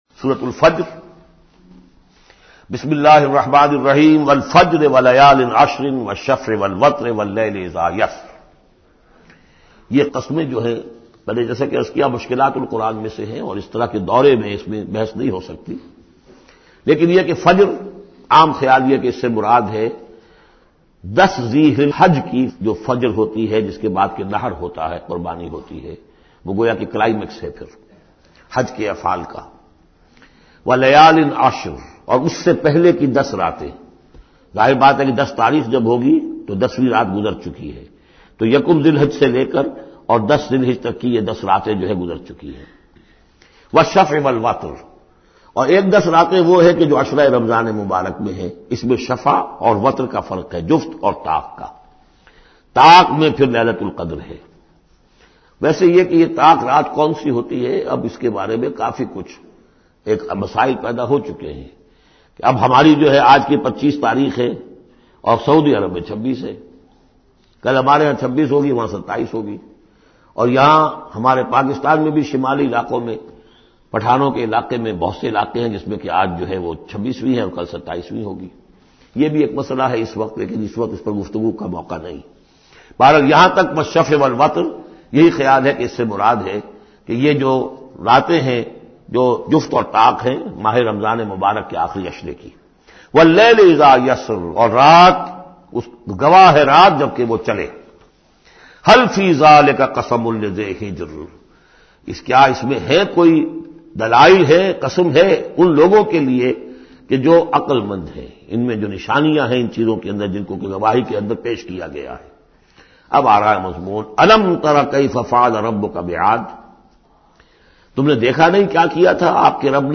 Surah Al-Fajr is 89 chapter of Holy Quran. Listen online mp3 urdu tafseer of Surah Al-Fajr in the voice of Dr Israr Ahmed.